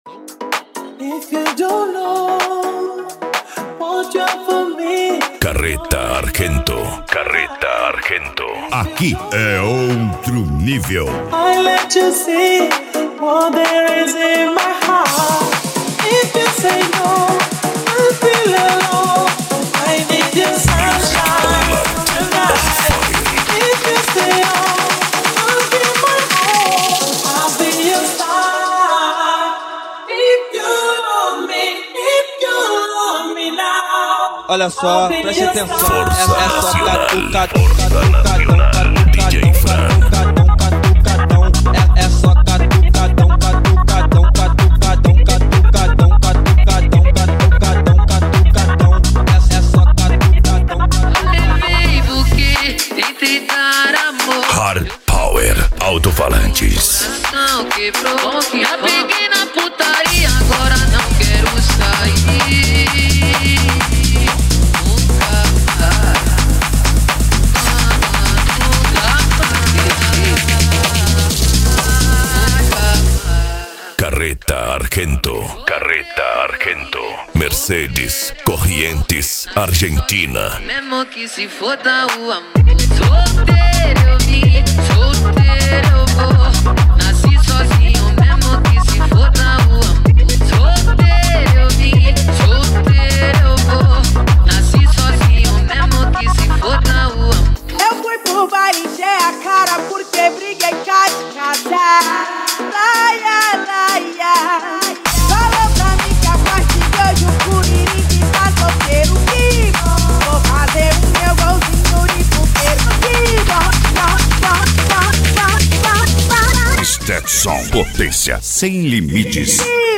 Bass
Euro Dance
Pagode
Remix